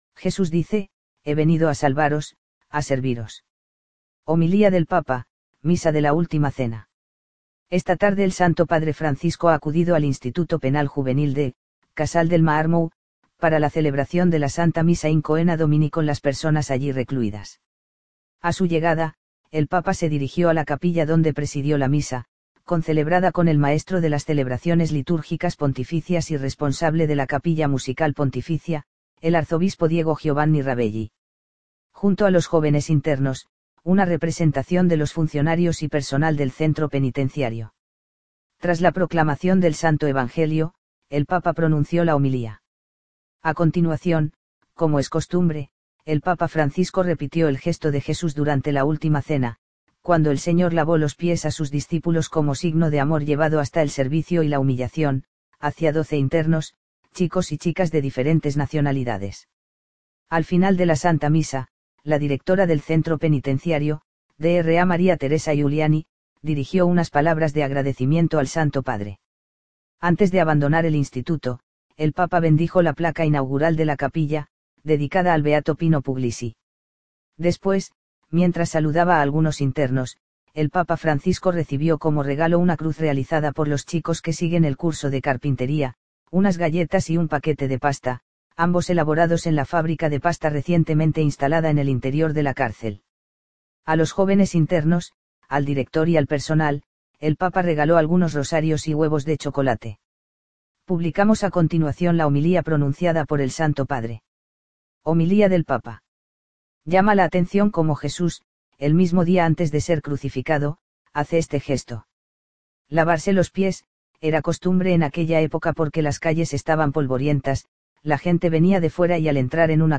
Homilía del Papa, Misa de la Última Cena
Esta tarde el Santo Padre Francisco ha acudido al Instituto Penal Juvenil de «Casal del Marmo» para la celebración de la Santa Misa “in Coena Domini” con las personas allí recluidas.
Tras la proclamación del Santo Evangelio, el Papa pronunció la homilía.